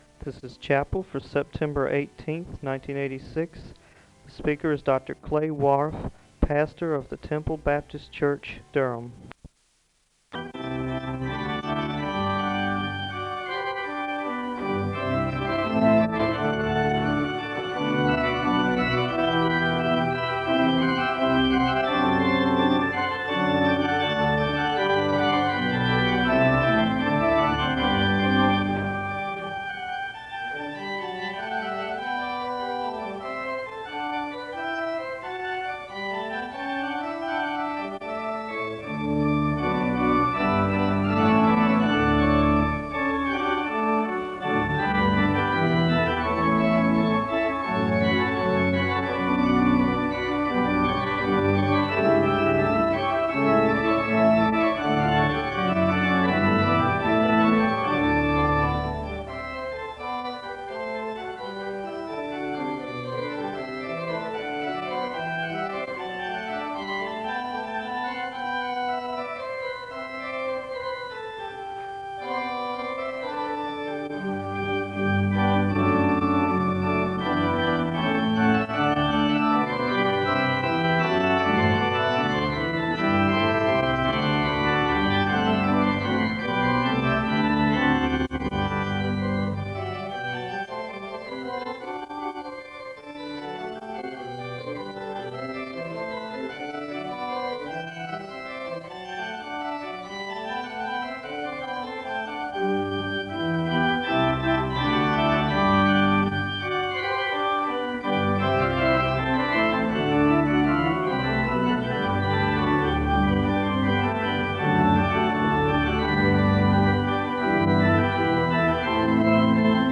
The service begins with organ music (0:00-3:51). There is a Scripture reading as a call to worship and a moment of prayer (3:52-5:38).
The choir sings an anthem (7:56-10:18).